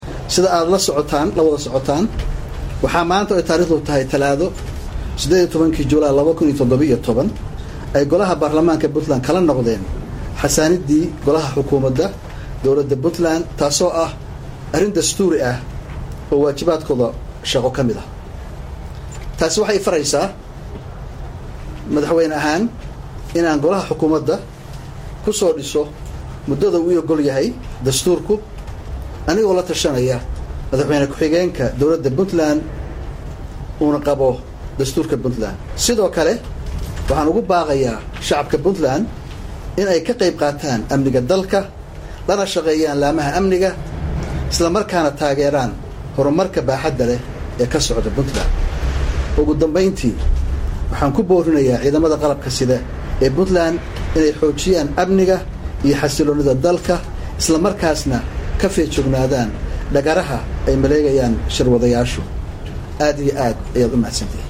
Gaas oo si kalsooni ku jirto uga hadlay Golahii wasiirada Maamulka Punland ee la riday | Goobsan Media Inc
Garoowe-Goobsan:- Madaxweynaha Maamulka Puntland Cabdi Wali Maxamed Cali Gaas oo Madaxtooyada Garowe Saxaafadda kula hadlay, ayaa sheegay inuu soo dhaweynaayo go’aanka ay gaareen Baarlamaanka Puntland.